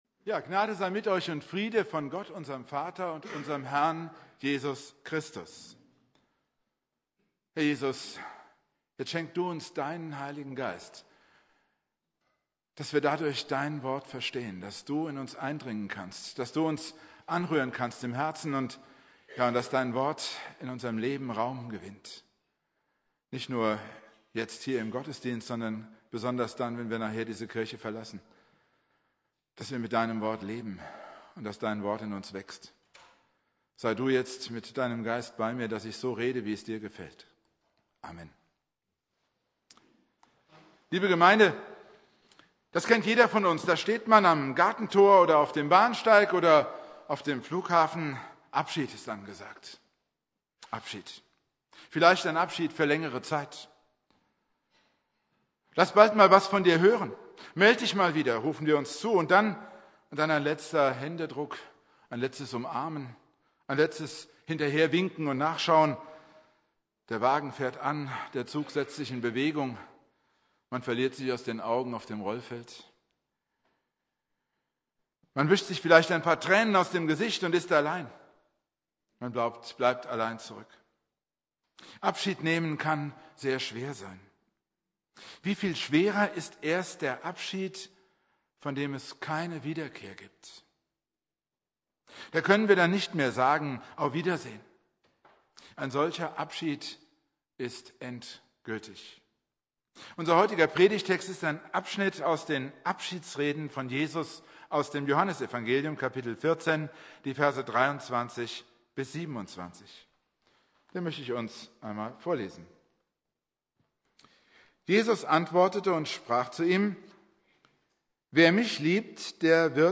Predigt
Pfingstsonntag Prediger